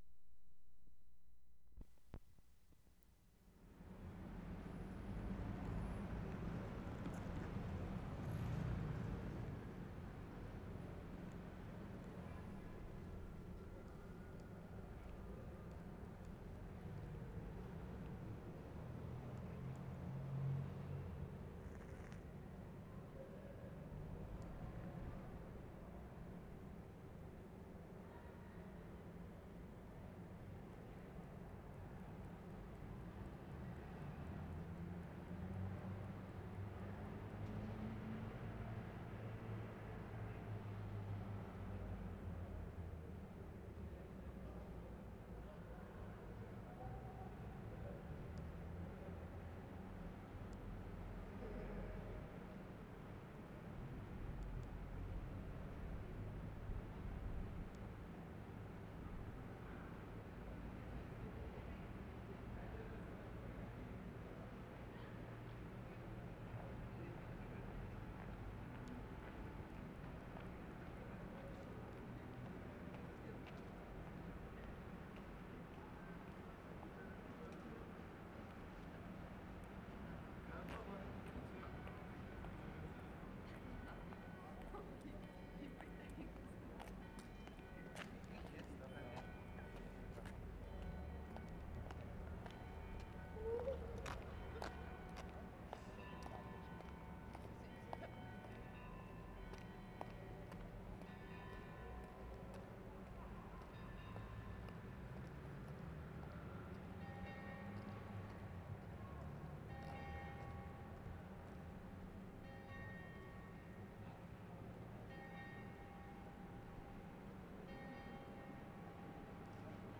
WORLD SOUNDSCAPE PROJECT TAPE LIBRARY
Salzburg, Austria March 15/75
MIDNIGHT BELLS
3. Bells from many different churches. Intermittent broadband noise from street washer. Note: only two sets of bells sound. High bell sounds the hour, low bell rings 12 times (in Bissingen the two lower bells each rang 12 times).